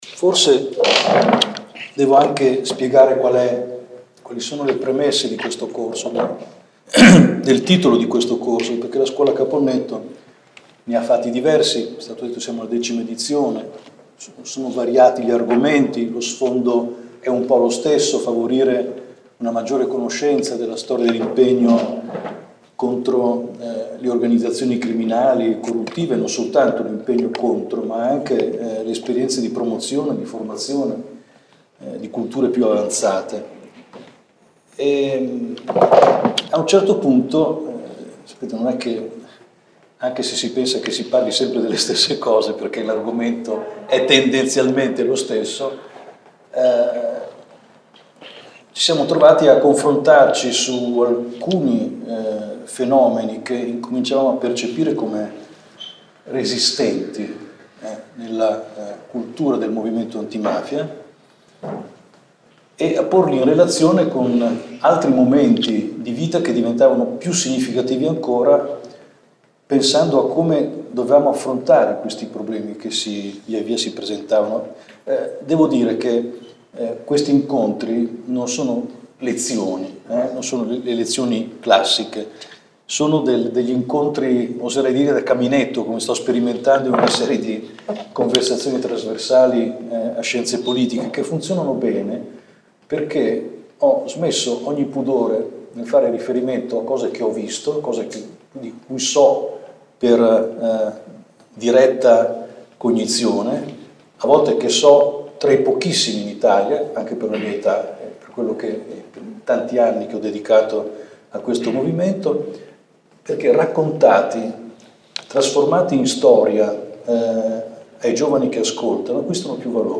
Prima lezione del nuovo ciclo di incontri (il decimo) ideato dalla Scuola di formazione “Antonino Caponnetto” e realizzato insieme a Radio Popolare. «Eroi dell’antimafia» è il titolo del racconto in cinque puntate che farà quest’anno il professor Nando dalla Chiesa, presidente della Scuola di formazione Antonino Caponnetto, presidente onorario di libera, ordinario di sociologia della criminalità organizzata all’università statale di Milano.